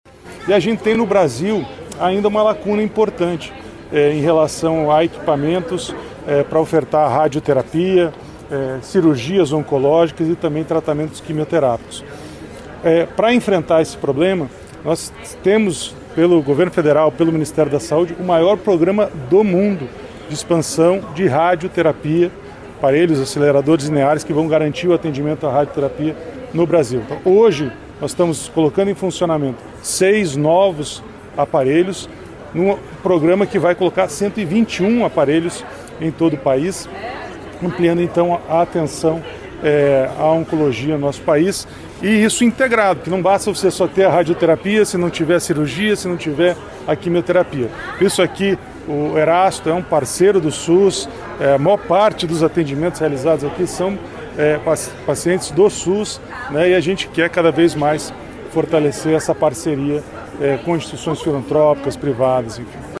Adriano Massuda falou sobre a oferta do programa que vai ajudar pacientes oncológicos, e destacou a importância do Hospital Erasto Gaertner.